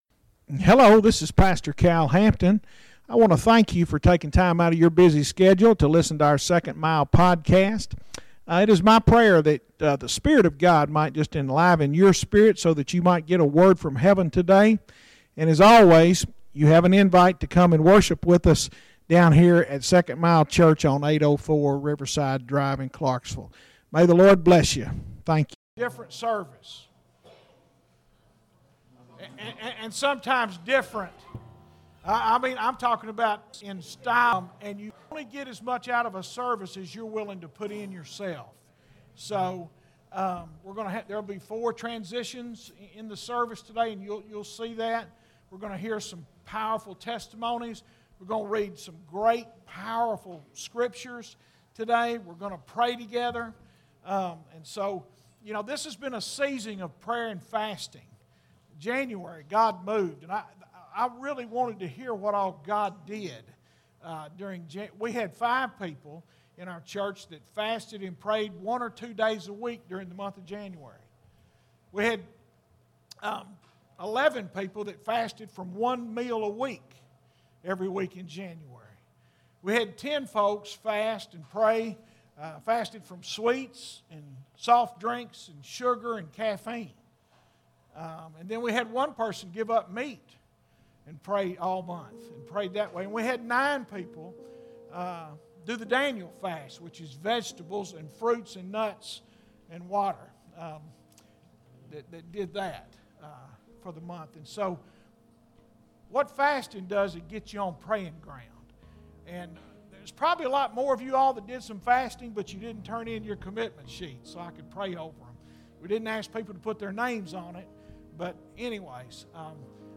Sermons Archive - Page 114 of 311 - 2nd Mile Church